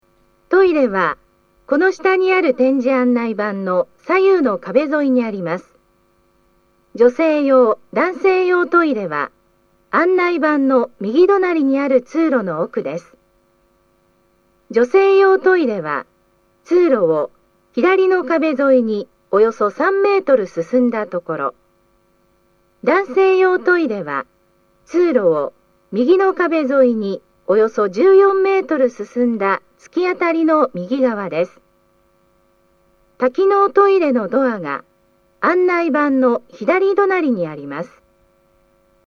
スピーカー種類 BOSE天井型
トイレ案内1
音声は、トイレ案内です。